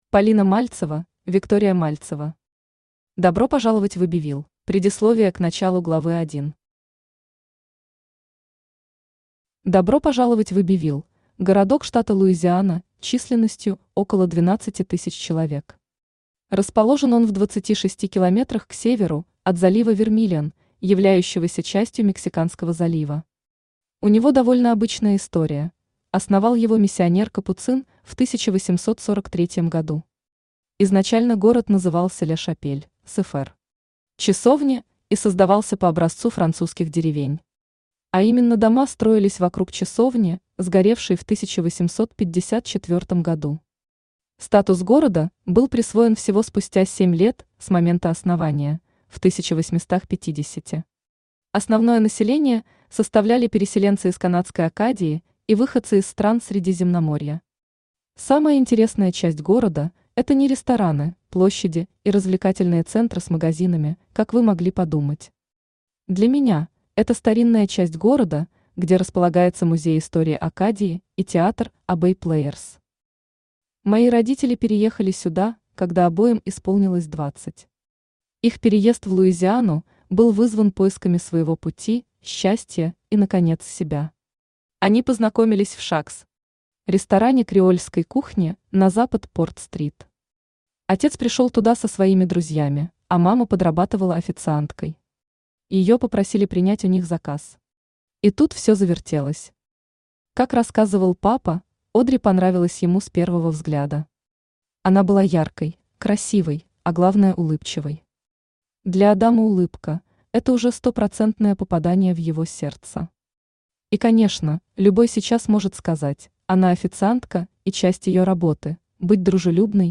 Аудиокнига Добро пожаловать в Эббивилл | Библиотека аудиокниг
Aудиокнига Добро пожаловать в Эббивилл Автор Виктория Викторовна Мальцева Читает аудиокнигу Авточтец ЛитРес.